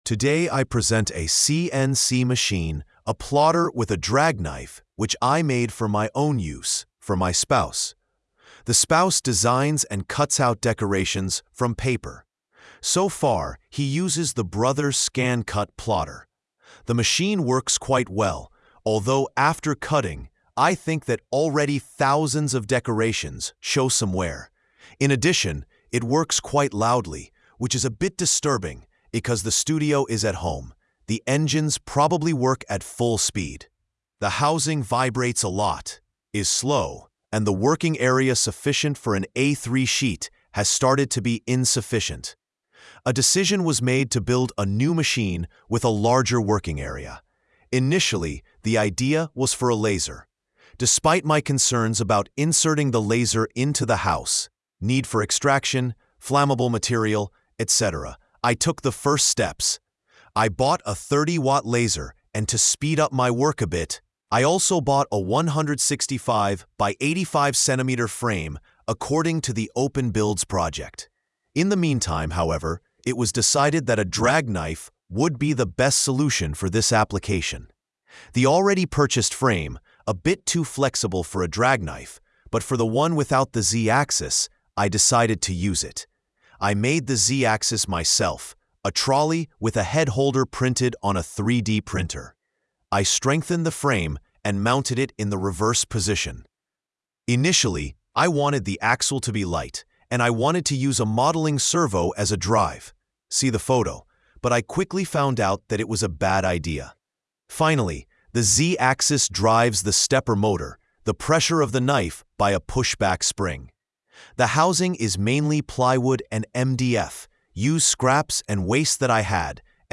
📢 Listen (AI):